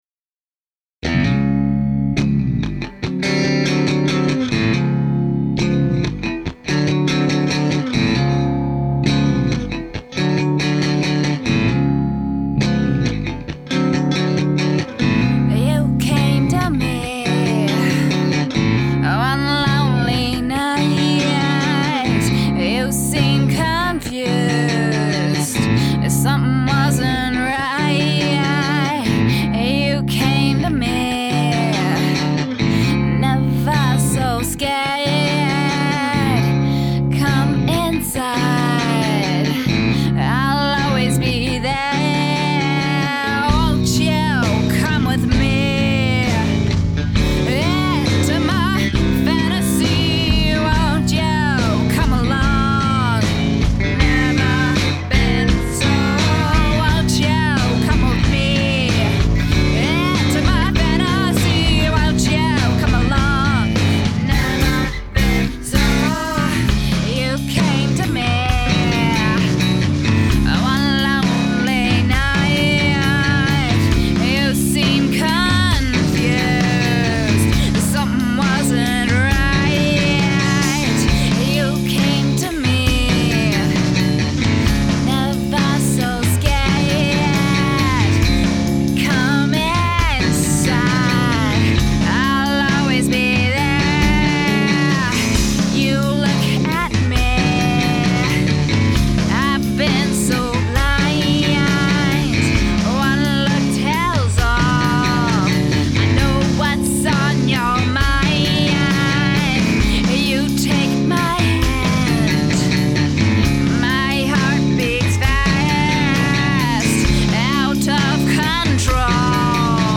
It was a 4 piece all female band, except for me.